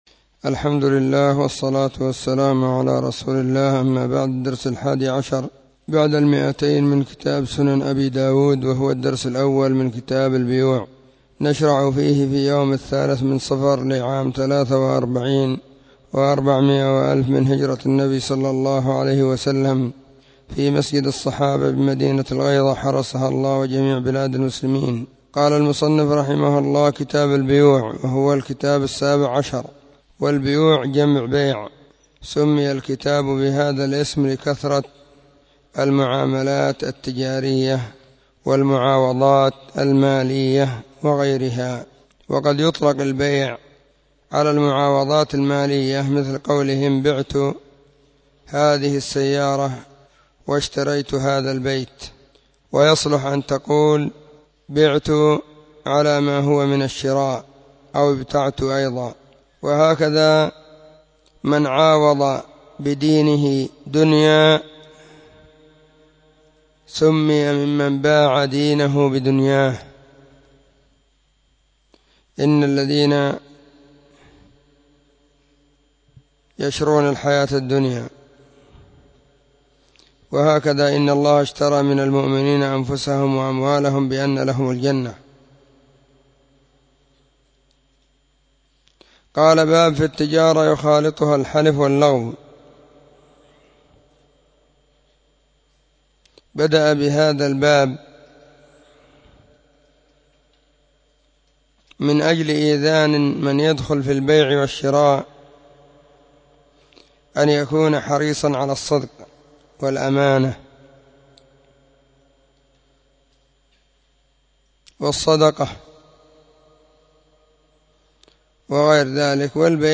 🕐 [بعد صلاة العصر في كل يوم الجمعة والسبت]